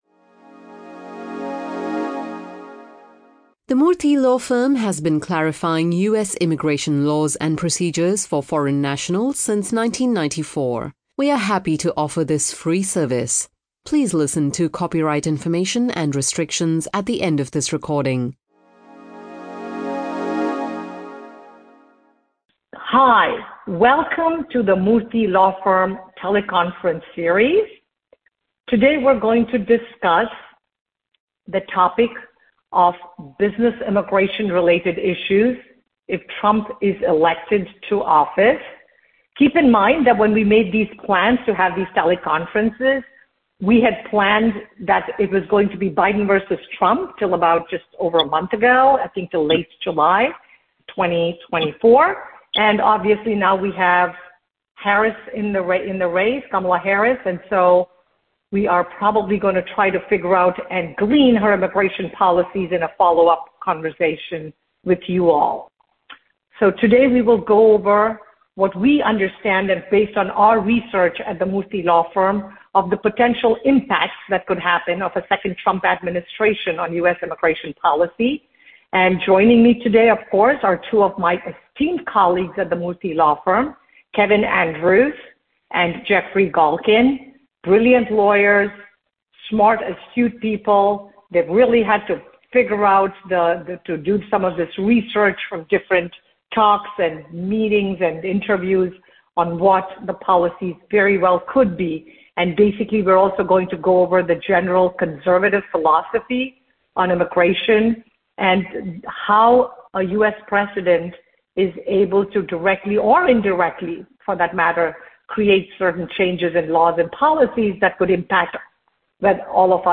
What are the potential issues for businesses in the area of U.S. immigration law if Trump is elected in 2024?These are discussed by Murthy Law Firm attorneys in this podcast that is a continuation of our series for U.S. employers.